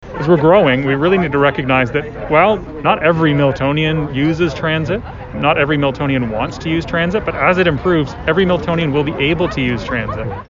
The announcement was made at the Milton Sports Centre yesterday morning by Milton MP Adam van Koeverden, MPP Parm Gill, Milton Mayor Gordon Krantz, and the Federal Minister of Infrastructure and Communities, Catherine McKenna.
Milton MP Adam van Koeverden says this is a commitment to improve Milton’s transit system.